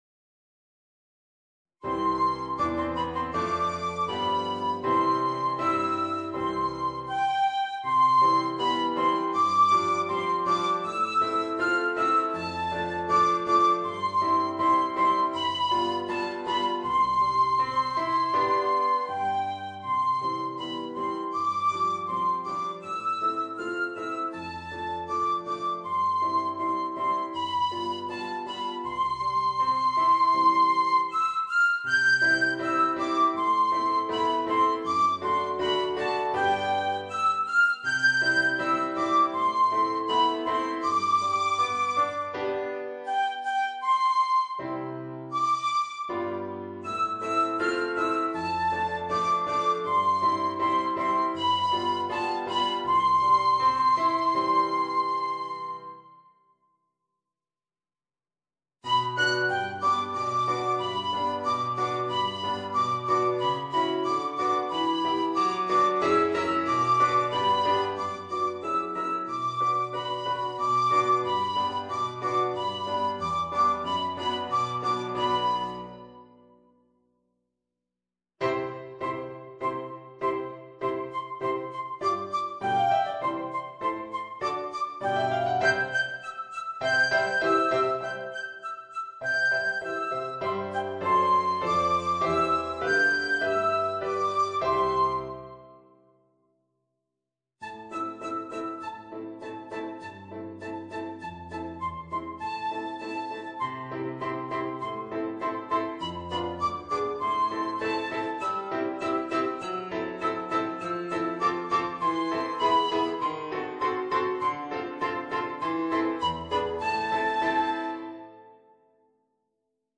Piccolo w/ Audio